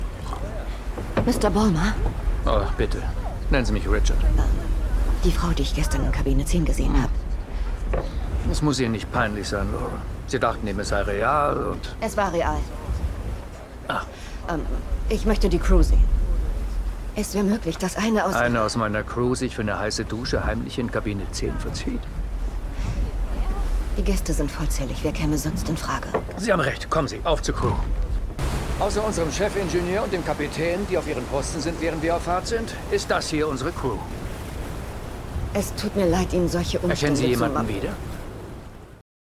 synchron-the woman in the cabin 10-nr.1
Philipp Moog ist die Feststimme von Ewan McGregor, Owen Wilson, Guy Pearce, Neil Patrick Harris und eine der Feststimmen von Orlando Bloom.